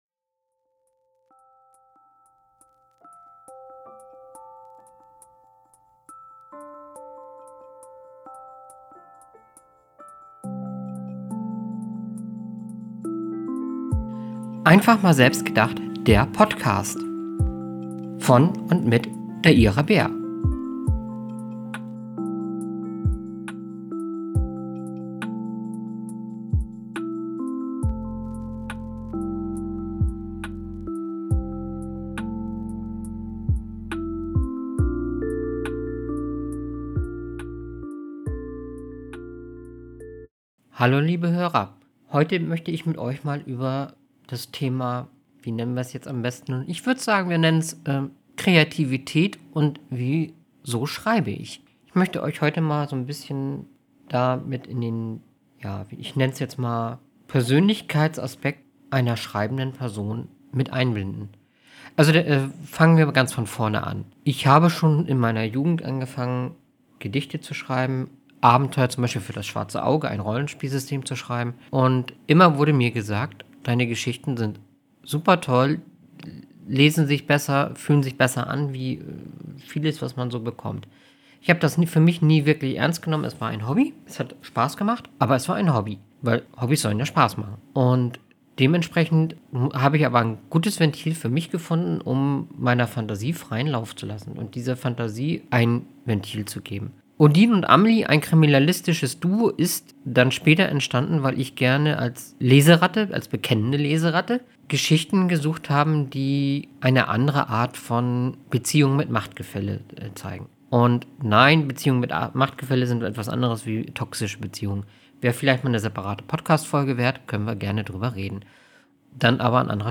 Und es gibt die versprochene Leseprobe Notes: Durch Dich Bin Ich Ein Besserer Mensch (Das Buch zur Leseprobe)